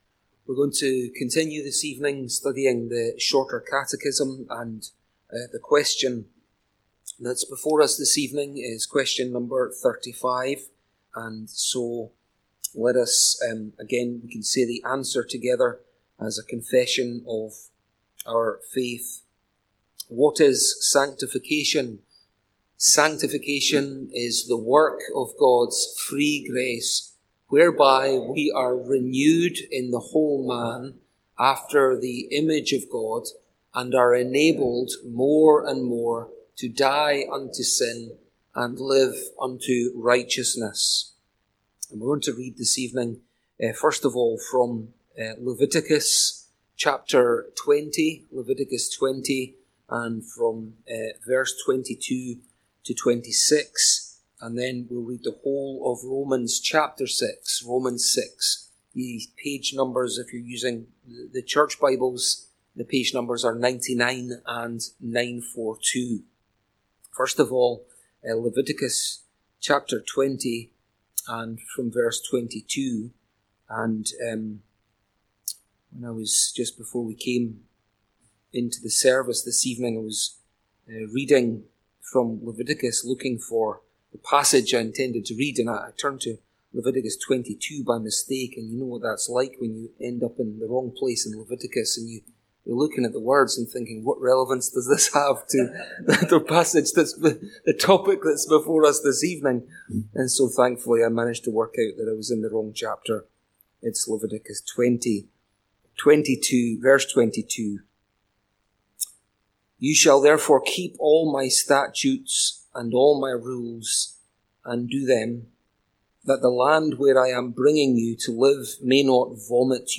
The Grace of Sanctification | SermonAudio Broadcaster is Live View the Live Stream Share this sermon Disabled by adblocker Copy URL Copied!